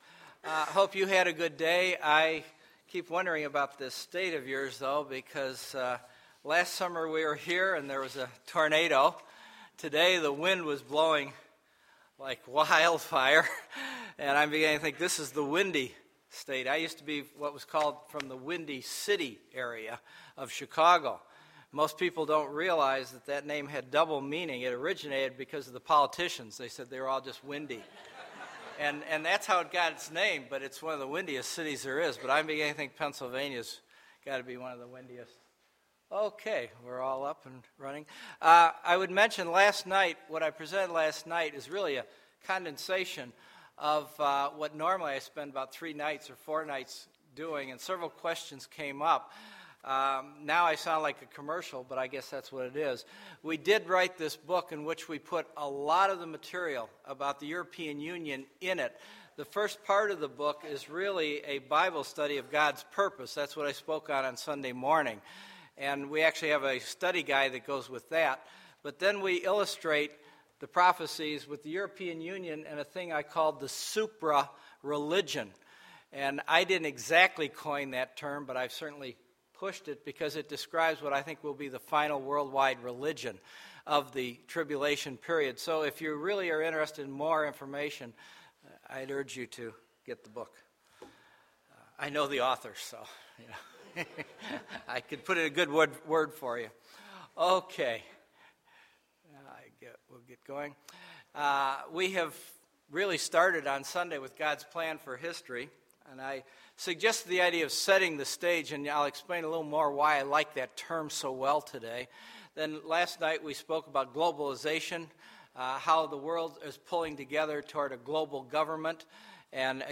Monday, March 26, 2012 – Spring Bible Conference – Monday PM
Sermons